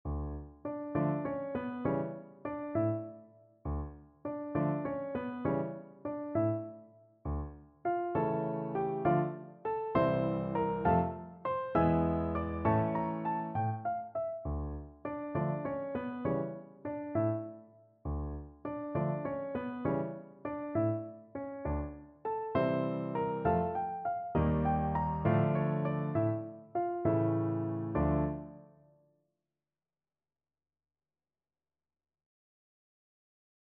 Free Sheet music for Piano Four Hands (Piano Duet)
Moderato
Classical (View more Classical Piano Duet Music)